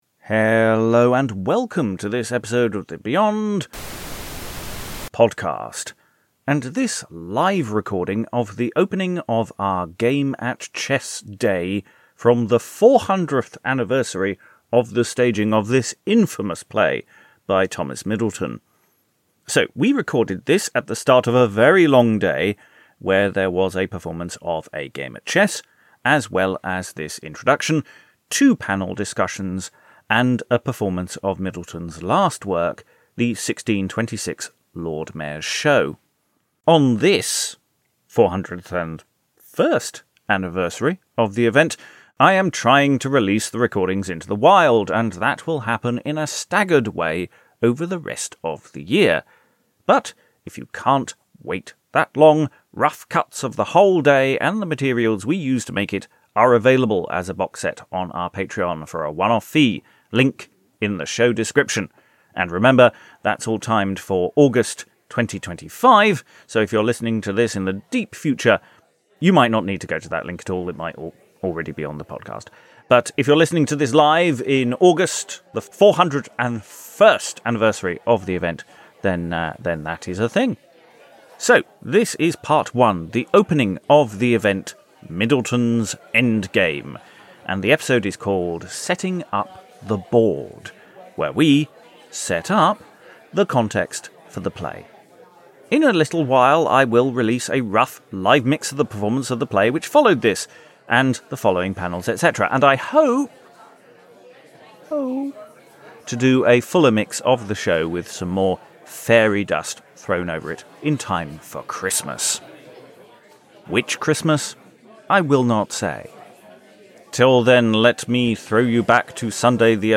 The whole event, as ever, was recorded, and we're releasing these over the next few months.
With readings of some of the sources, letters, and gossip behind the political theatre of the time.